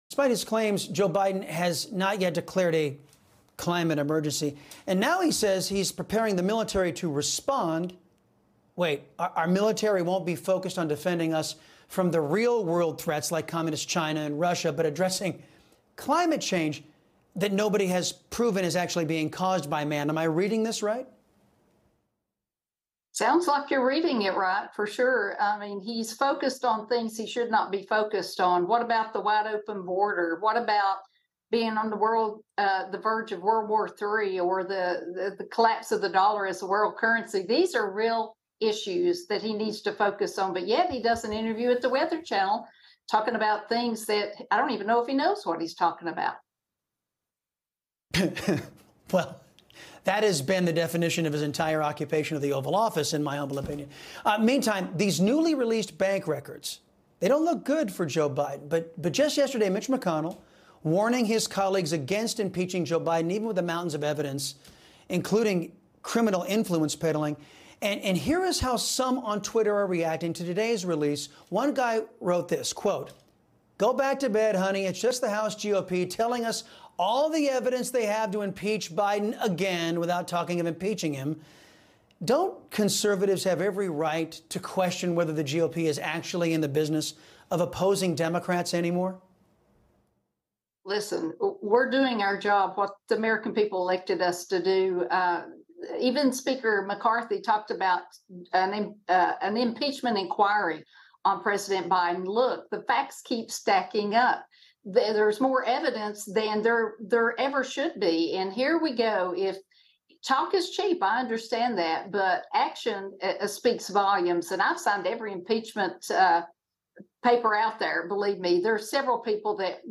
Biden Gives Rare Interview to The Weather Channel